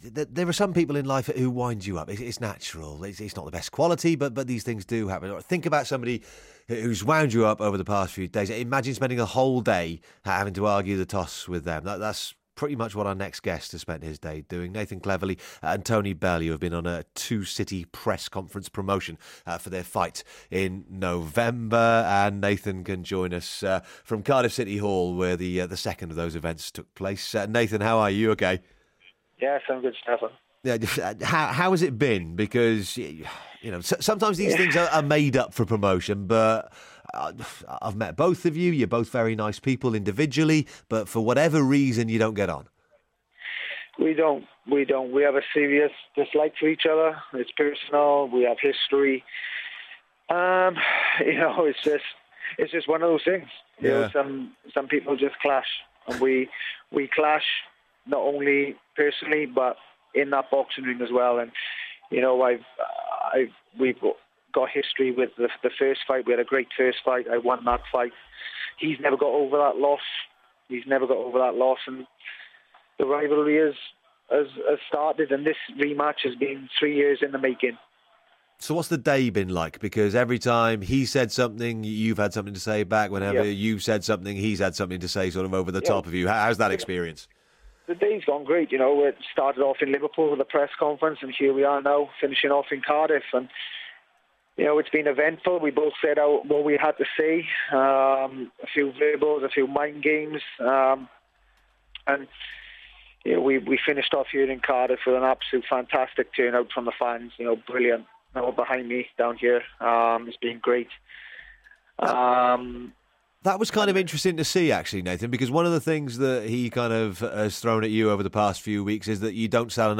Nathan Cleverly and Tony Bellew speak to Radio Wales Sport ahead of their rematch in Liverpool on November 22nd